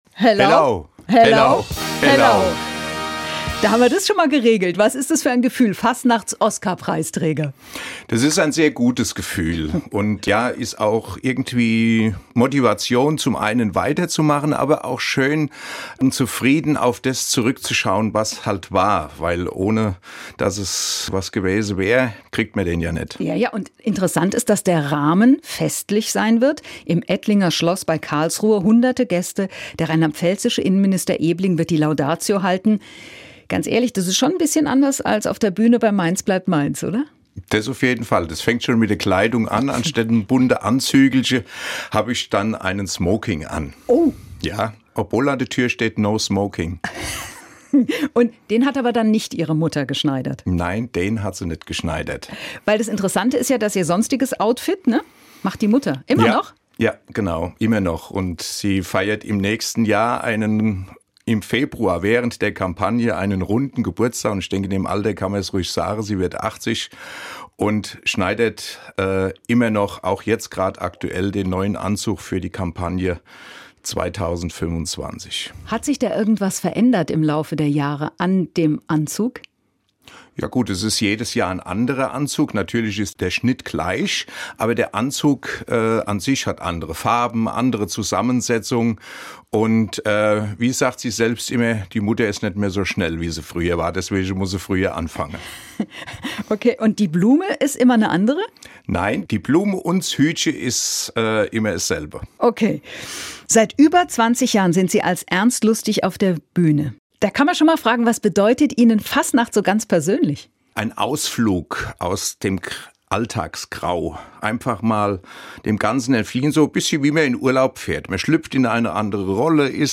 Das Interview führte
Interview mit